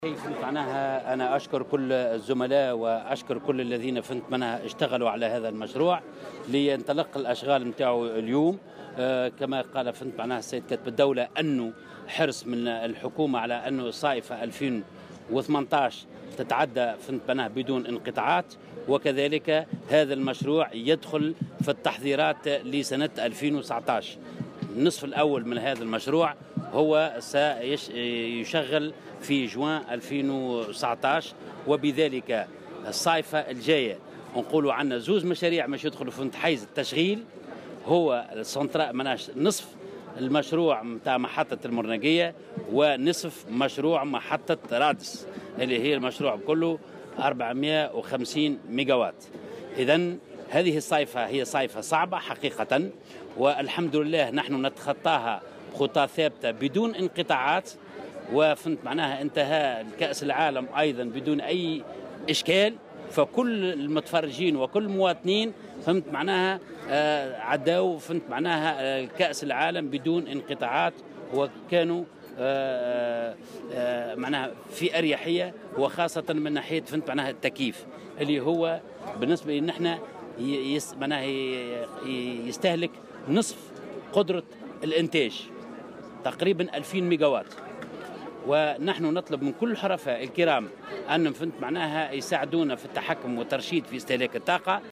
وأضاف في تصريح لمراسل "الجوهرة أف أم" أن الأشغال ستمتد على 22 شهرا، وسيدخل الجزء الأول من هذا المشروع (312,5 ميغاواط) حيز الاستغلال خلال شهر جوان 2019، إضافة إلى إنجاز مشروع محطة إنجاز محطة لتوليد الكهرباء في رادس.